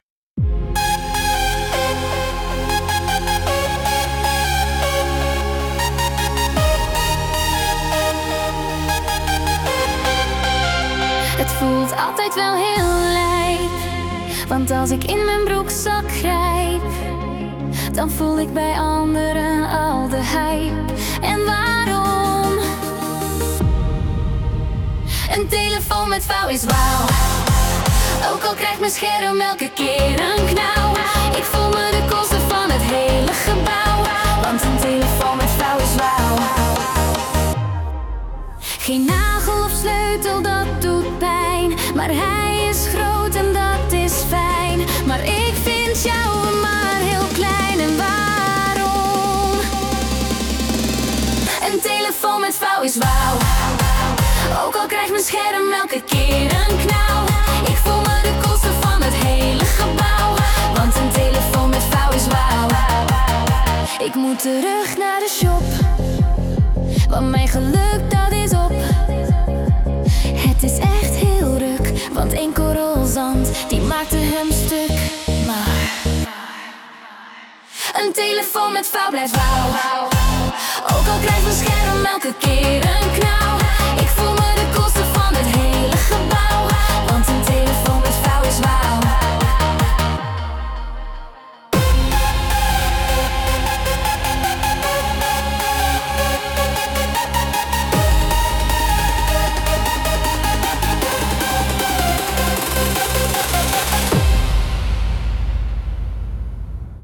melodic hardstyle
De tekst is niet Ai-gegenereerd, alleen de stem en muziek, net als bij 'wifi 7' ;)